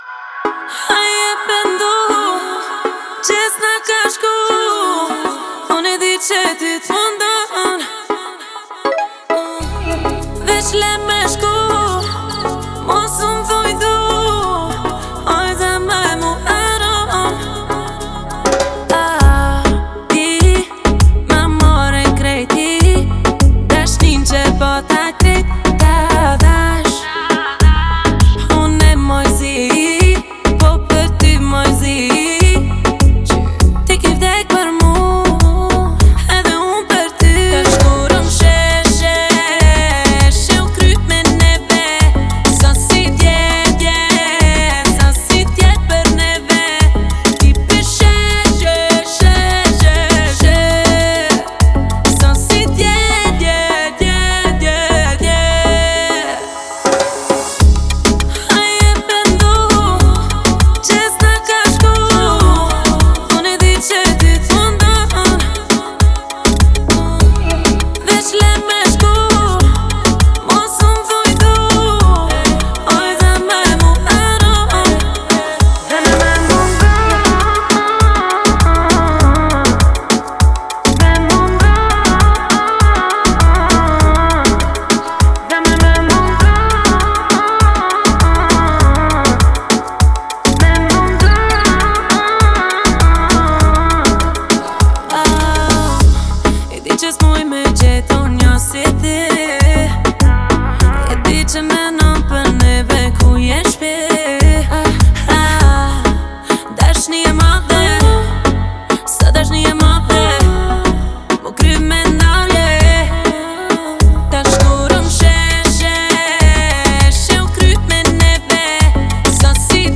это динамичная песня в жанре поп с элементами R&B